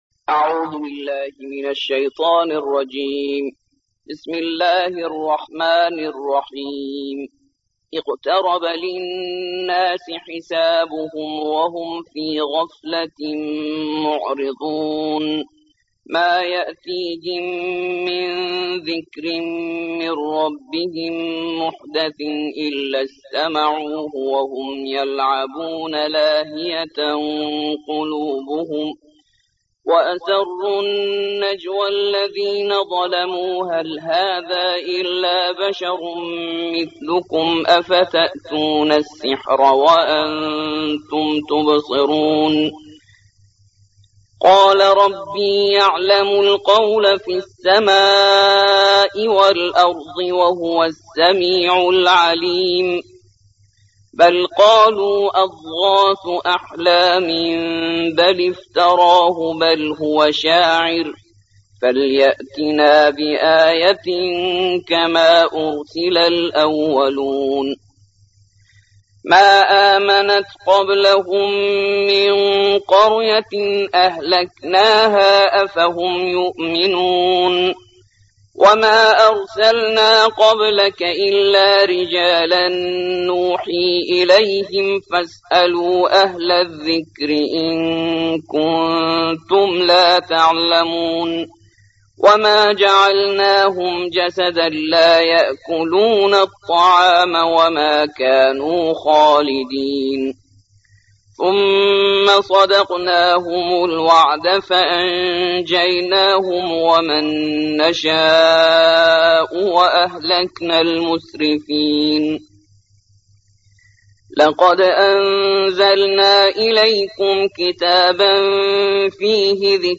21. سورة الأنبياء / القارئ